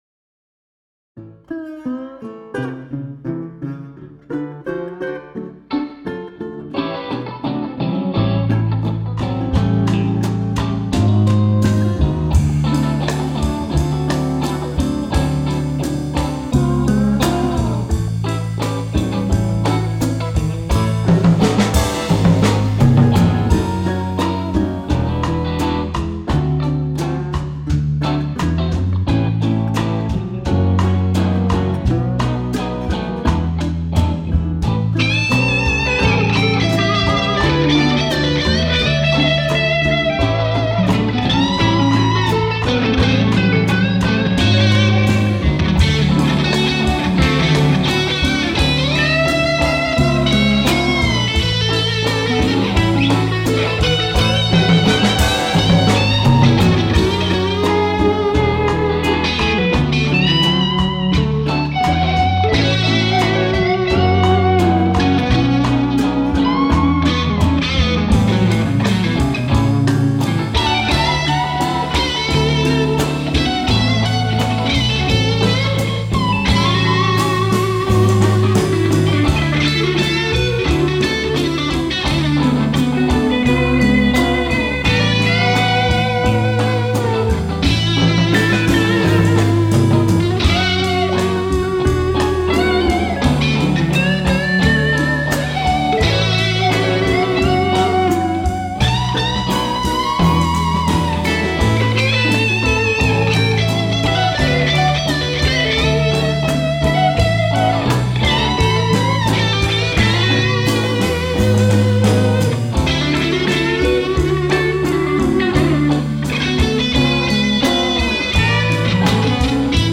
SoloGit1+2 + Bass + Dobro1+2 + RGit1+RGit2 + drums    Nun tummeln sich also wirklich alle neun Instrumentalisten auf der Bühne, auch die zwei Schlagzeuge - aber erstmal nur für 44 sec.
Hier nun endlich das Stück mit allen Stimmen in voller Länge!